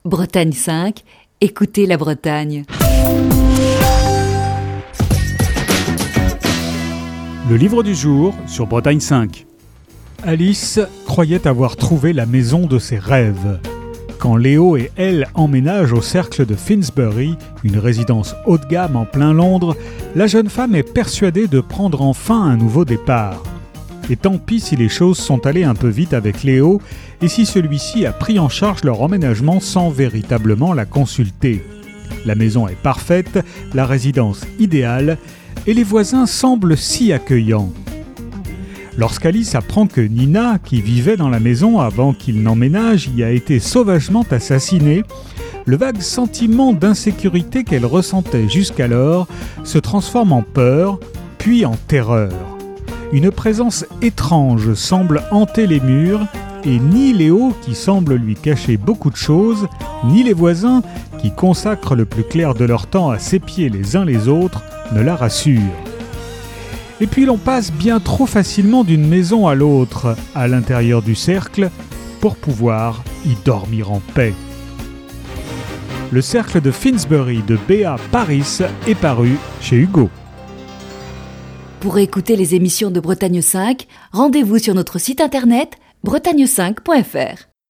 Chronique du 17 mai 2021.